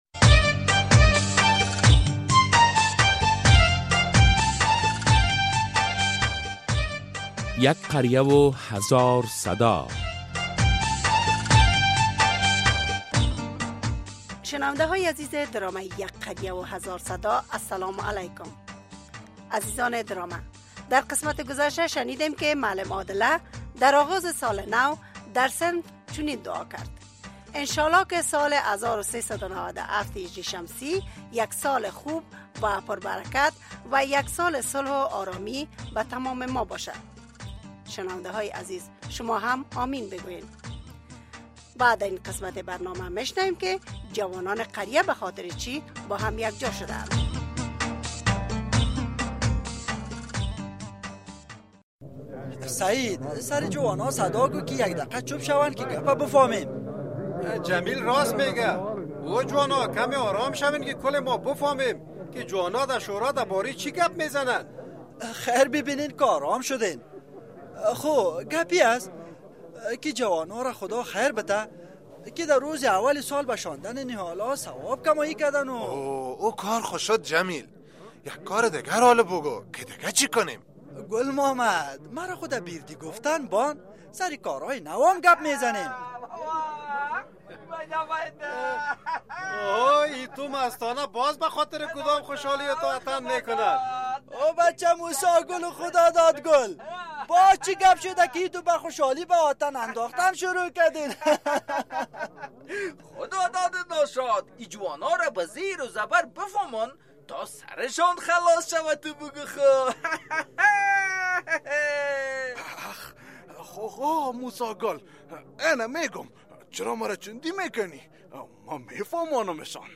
درامه ۲۳۶